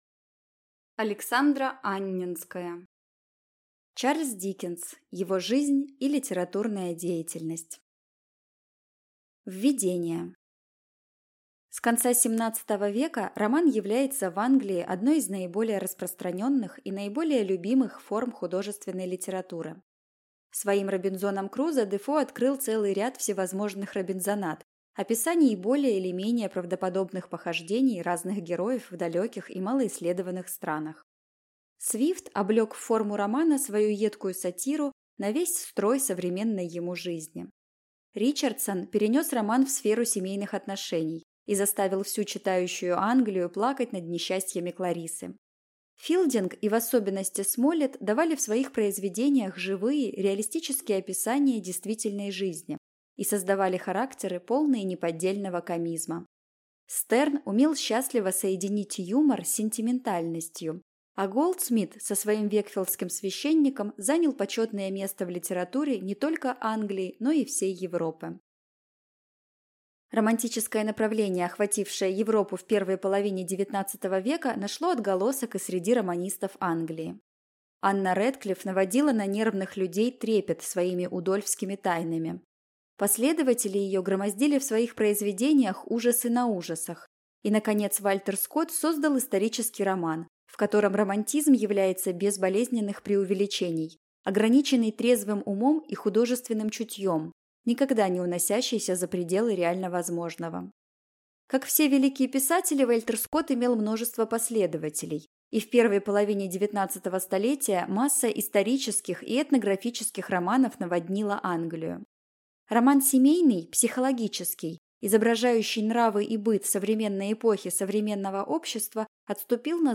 Аудиокнига Чарльз Диккенс. Его жизнь и литературная деятельность | Библиотека аудиокниг